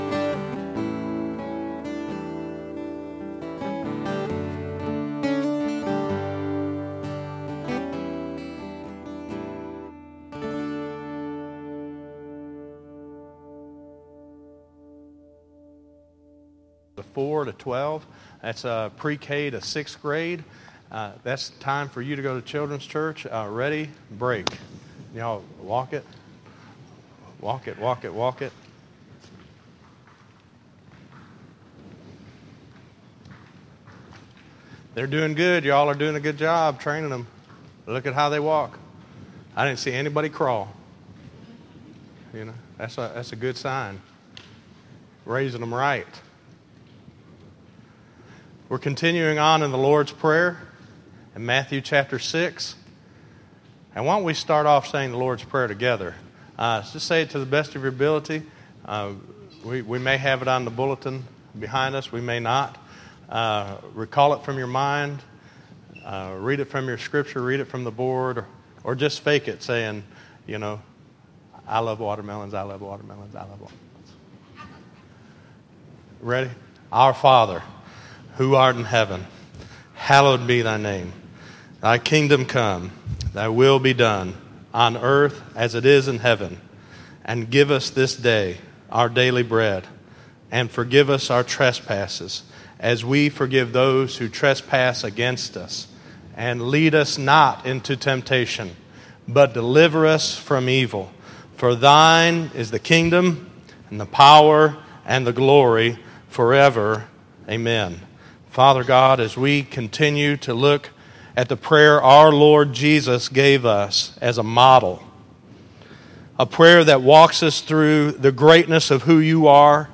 Listen to Prayer Life = Life Prayer 5 - 06_22_2014_sermon.mp3